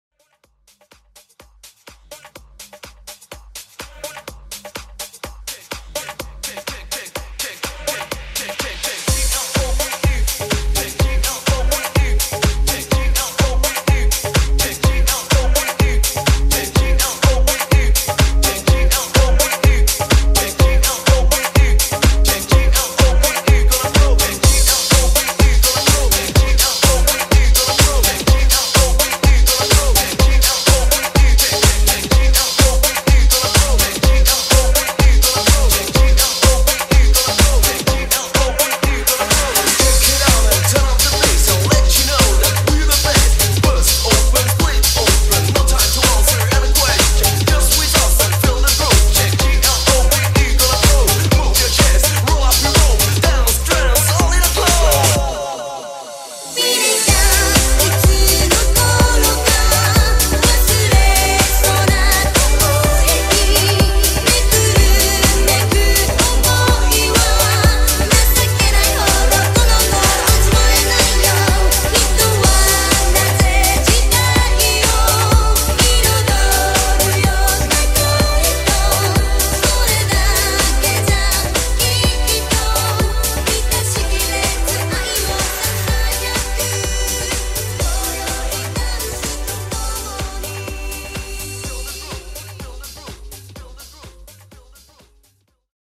90s Pop House Reboot